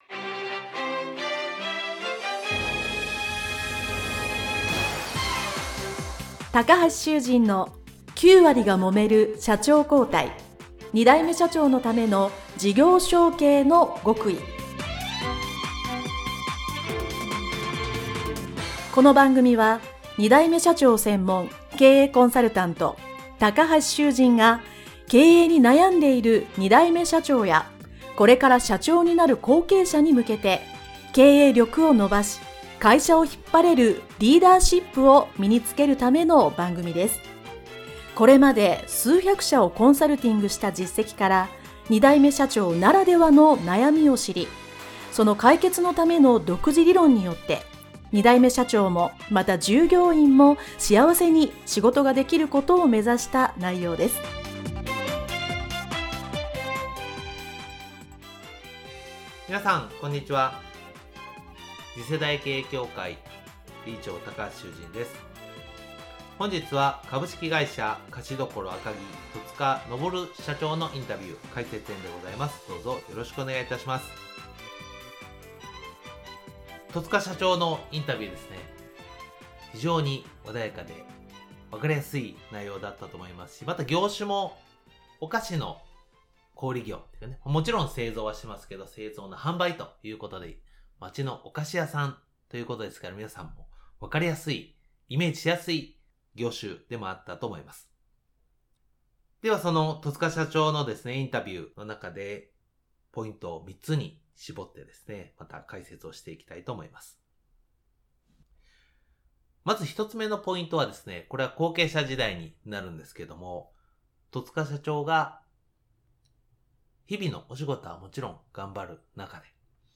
【インタビュー解説編】